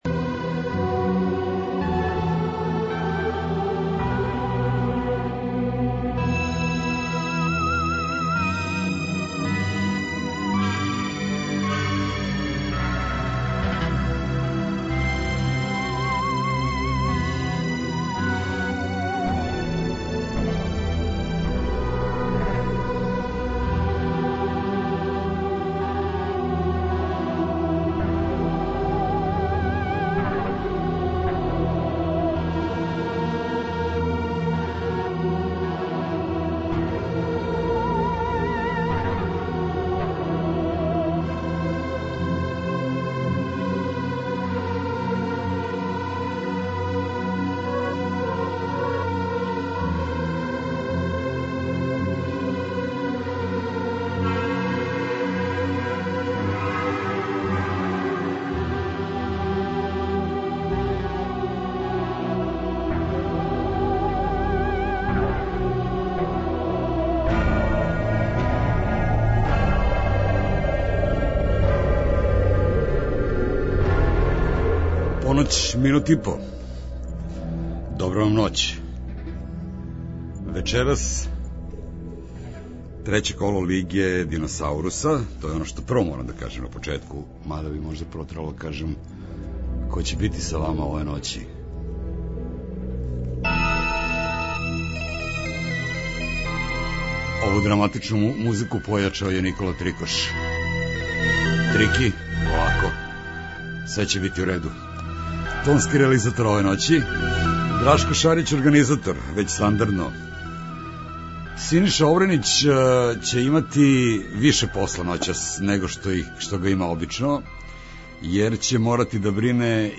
Средом од поноћи - спој добре рок музике, спортског узбуђења и навијачких страсти.